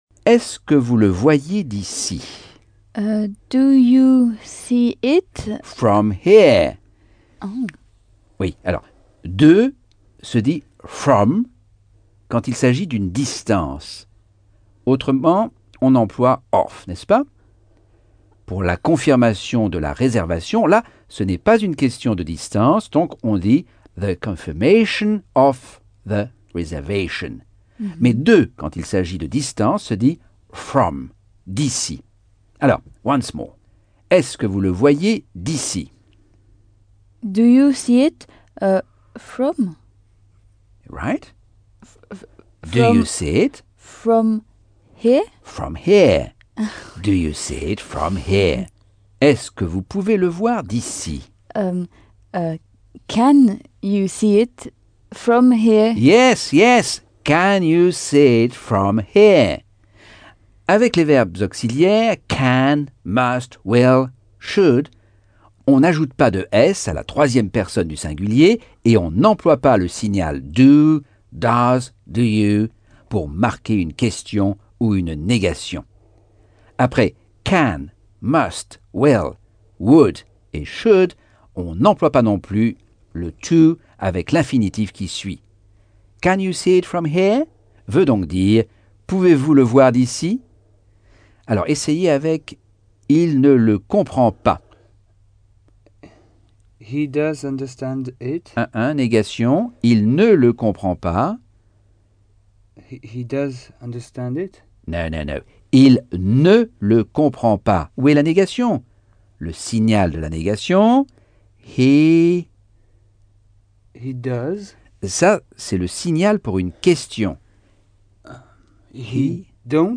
Leçon 4 - Cours audio Anglais par Michel Thomas - Chapitre 5